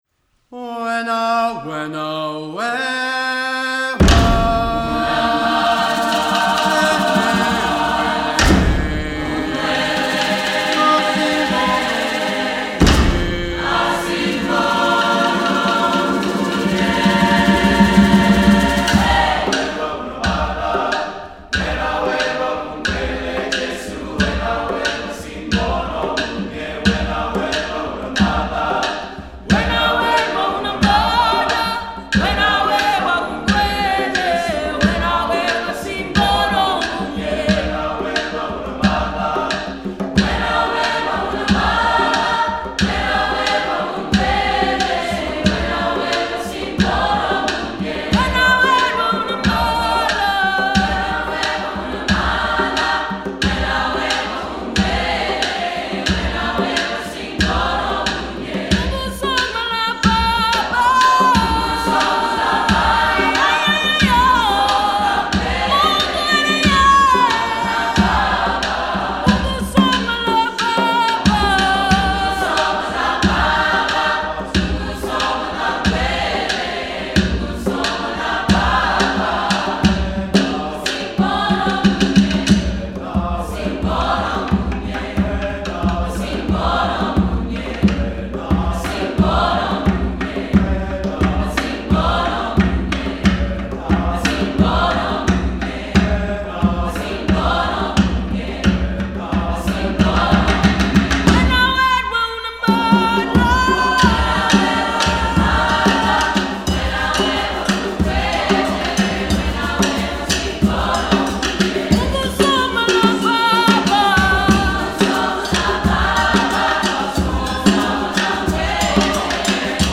Composer: Traditional isiZulu Song
Voicing: SATB divisi a cappella